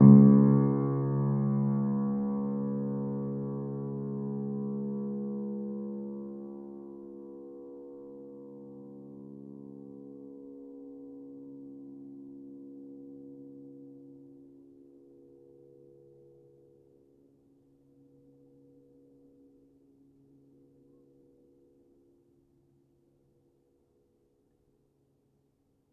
healing-soundscapes/Sound Banks/HSS_OP_Pack/Upright Piano/Player_dyn2_rr1_008.wav at main